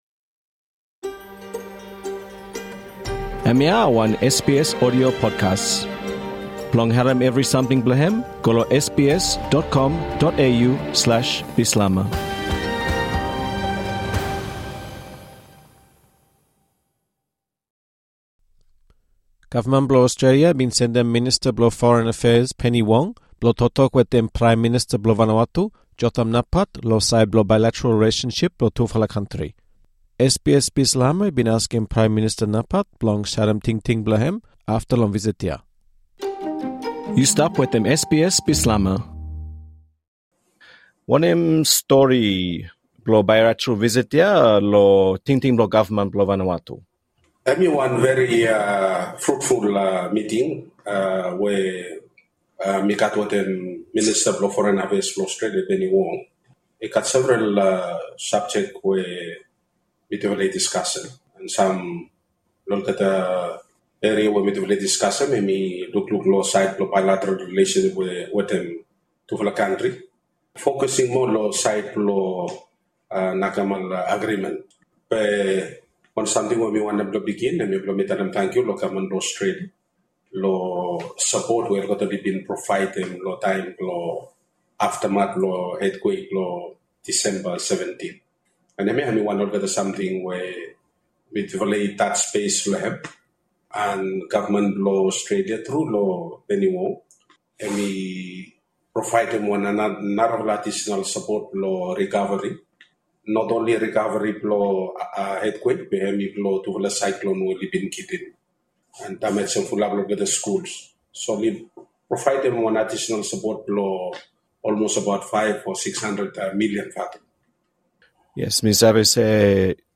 Kavman blo Ostrelia i bin sendem Minista blo Foren Affairs, Penny Wong, blo toktok wetem Prae Minista blo Vanuatu Jotham Napat lo saed blo bilateral relesonsip blo tufala kantri ia. SBS Bislama i bin askem Prae Minista Napat long wan eksklusiv intaviu blong sarem tingting blem afta long visit ia.